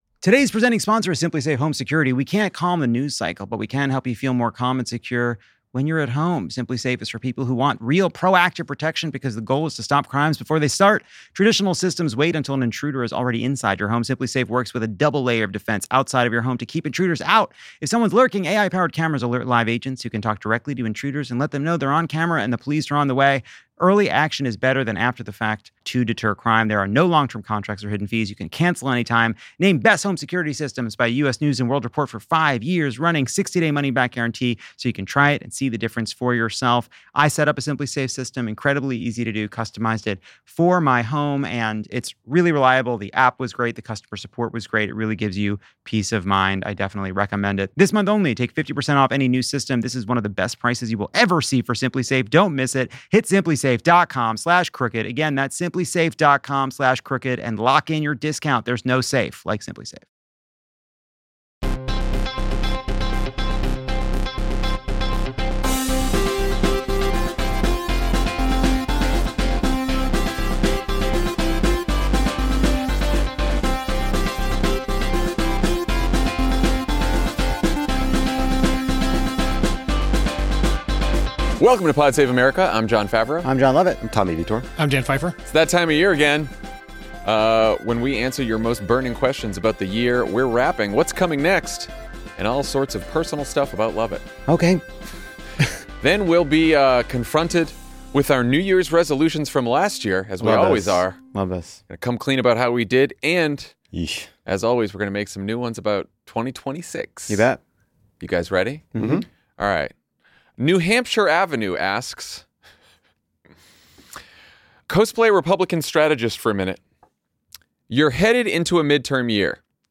Jon, Lovett, Dan, and Tommy answer your questions about the upcoming midterms, early bets on 2028, what they got wrong about this year, and Lovett's future reality television career. Then, they listen back to their 2024 New Year's resolutions and set ones they hope to actually keep in 2025.